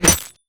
bullet_impact_glass_06.wav